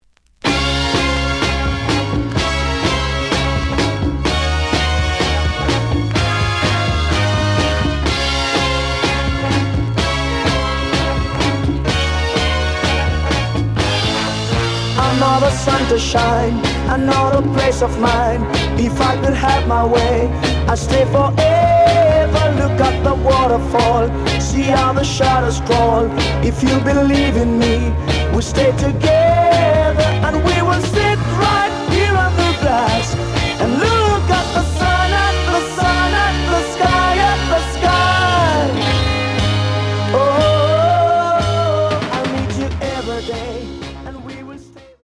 The audio sample is recorded from the actual item.
●Format: 7 inch
●Genre: Reggae